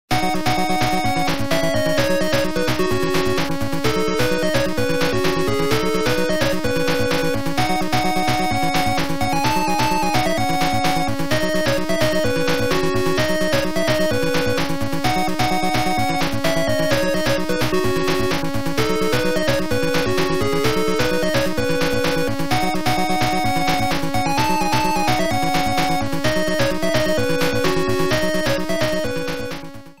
Trimmed to 30 seconds, applied fadeout